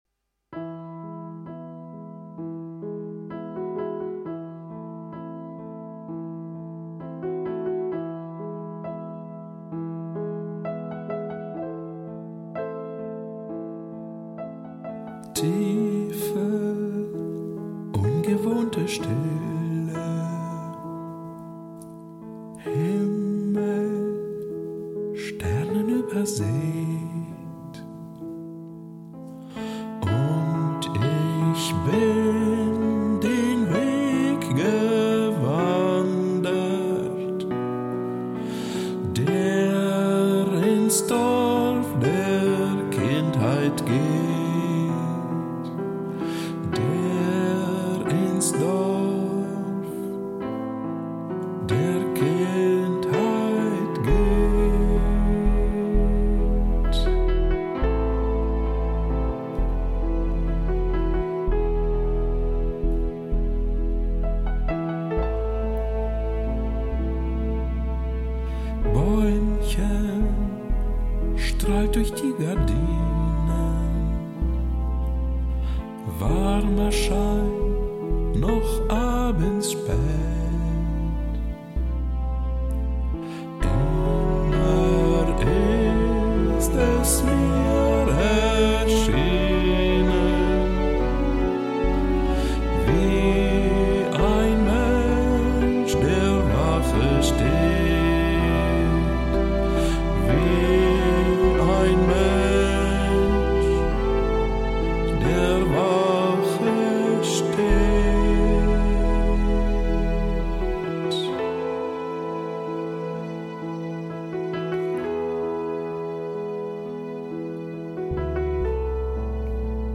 So, ich habe jetzt mal Bläser und zusätzliche Geigen hinzugefügt, gibt dann etwas mehr Fülle.